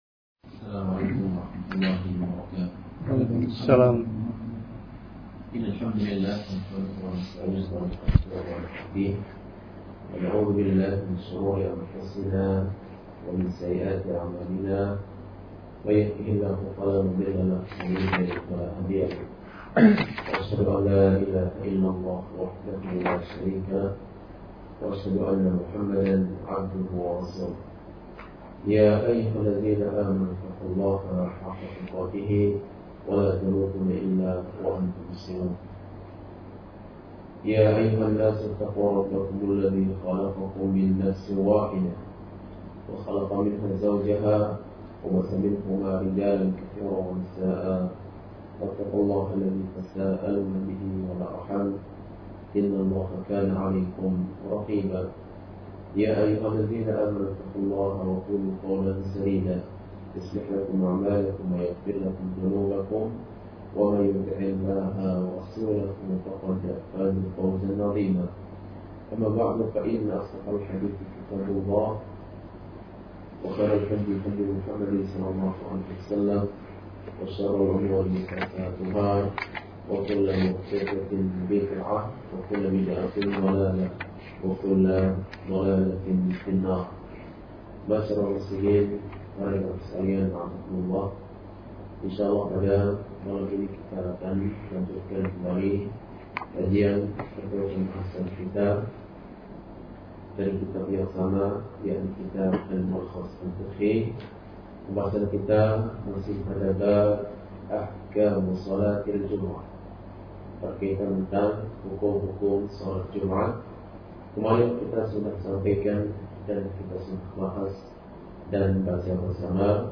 Kajian Ahad – Doha Membahas